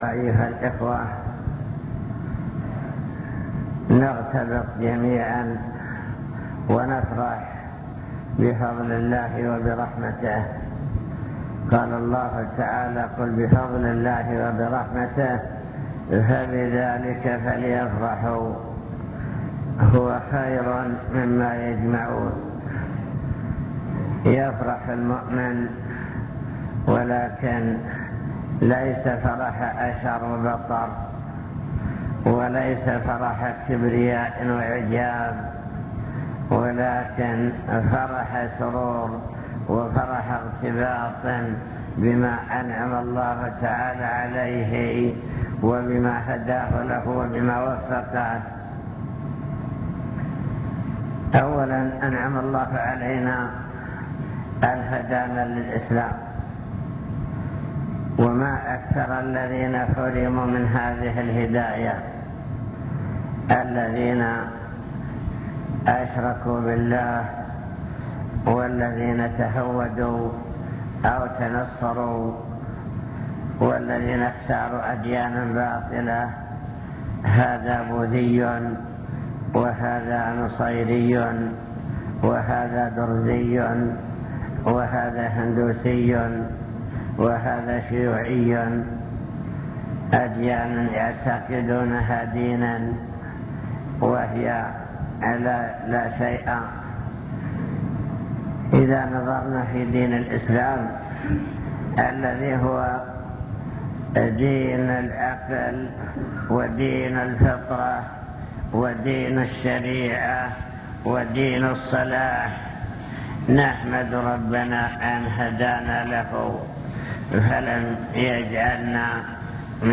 المكتبة الصوتية  تسجيلات - محاضرات ودروس  محاضرة بعنوان شكر النعم (2) نعم الله تعالى وعظمها